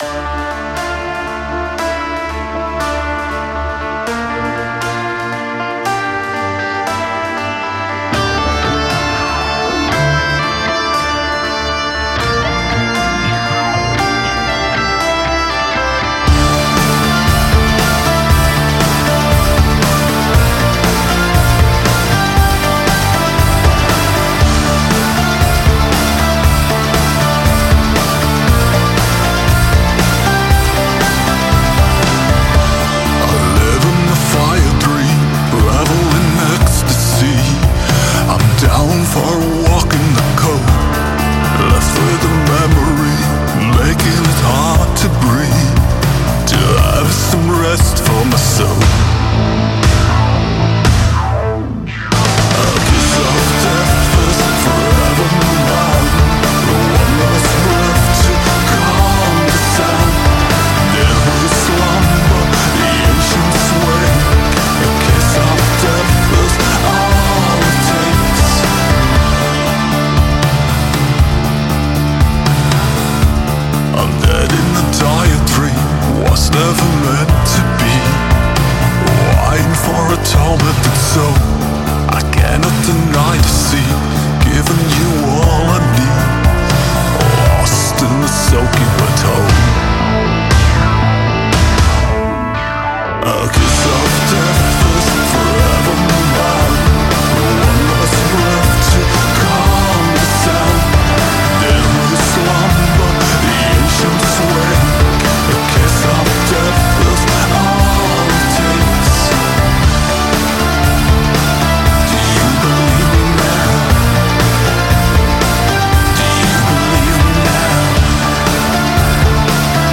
Меланхоличная готика.